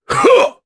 Ricardo-Vox_Attack1_jp.wav